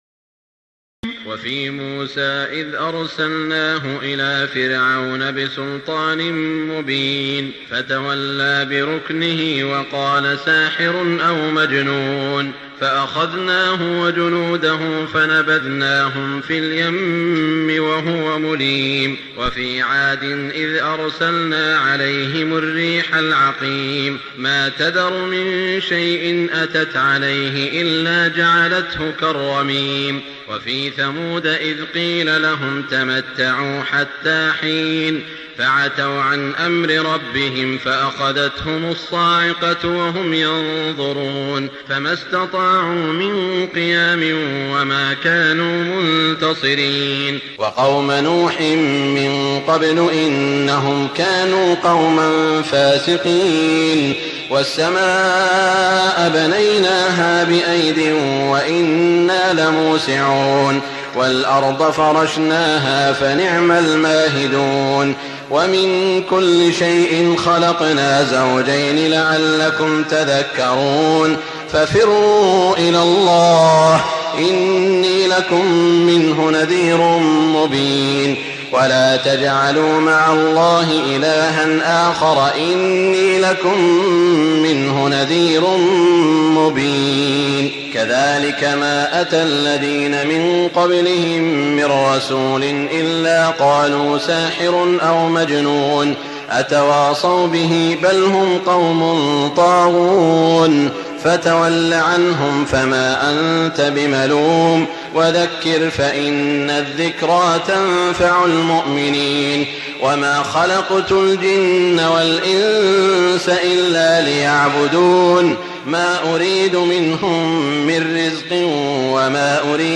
تراويح ليلة 26 رمضان 1419هـ من سور الذاريات (38-60) و الطور و النجم و القمر Taraweeh 26 st night Ramadan 1419H from Surah Adh-Dhaariyat and At-Tur and An-Najm and Al-Qamar > تراويح الحرم المكي عام 1419 🕋 > التراويح - تلاوات الحرمين